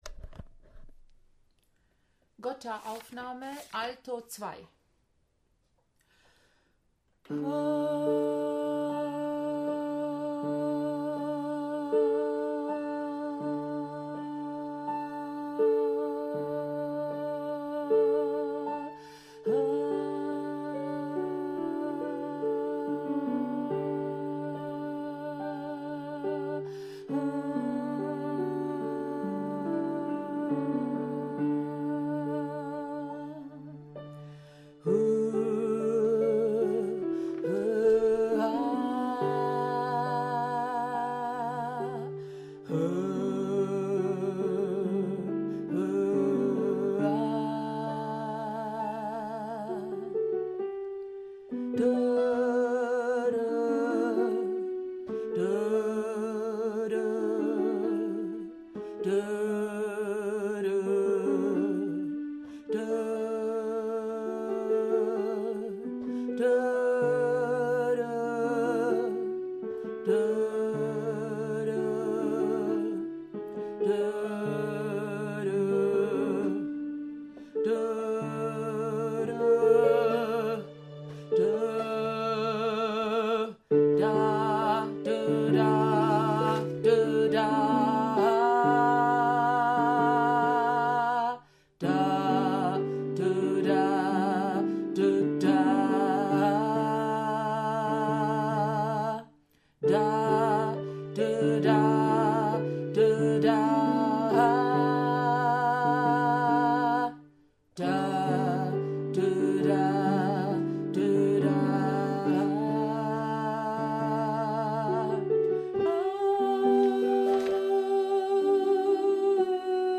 Gota-Alt2.mp3